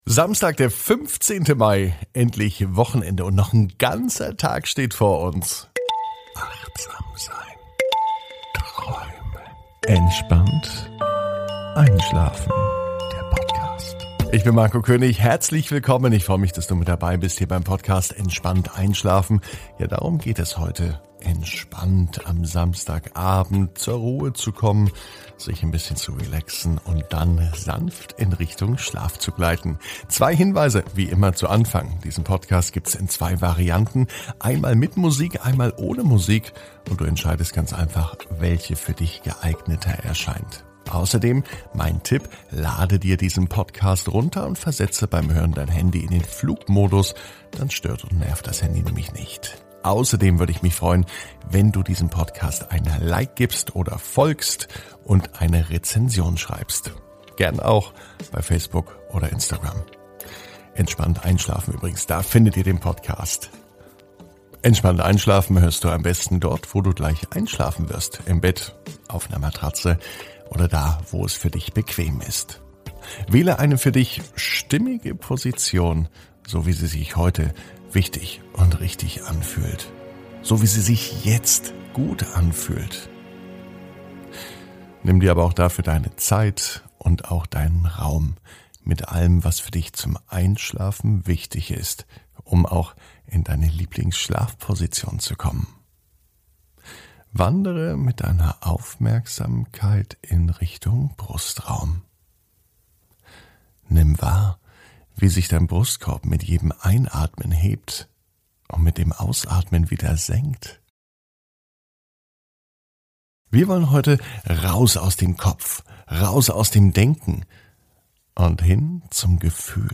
(ohne Musik) Entspannt einschlafen am Samstag, 15.05.21 ~ Entspannt einschlafen - Meditation & Achtsamkeit für die Nacht Podcast